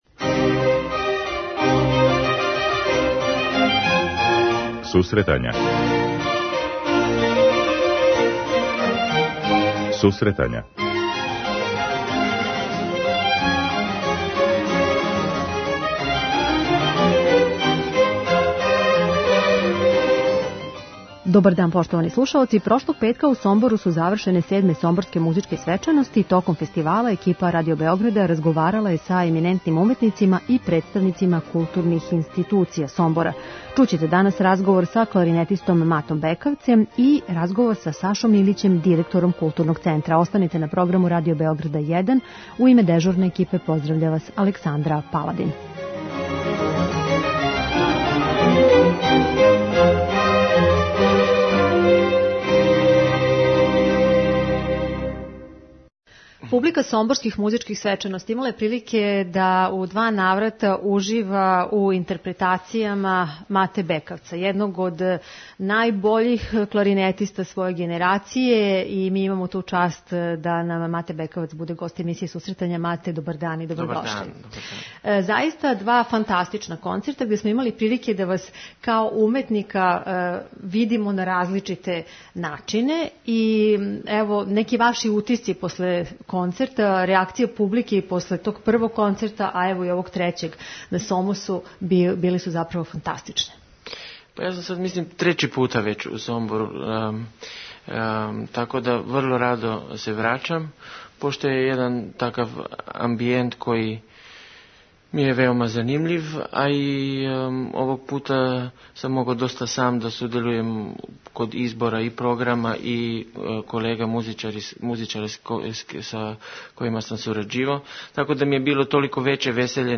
Прошлог петка у Сомбору су завршене 7. Сомборске музичке свечаности. Током фестивала екипа Радио Београда 1 разговарала је са еминентним уметницима и представницима културних институција.